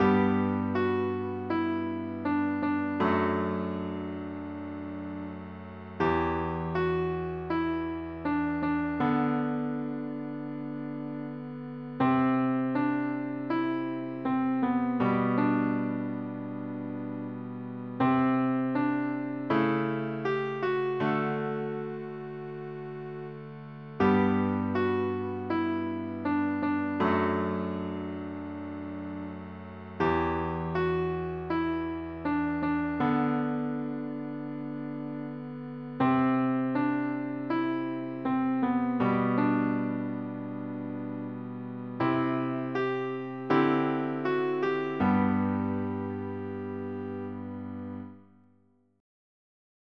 Can-You-See-the-Trees-piano-slow-x1-PlayScore-version.mp3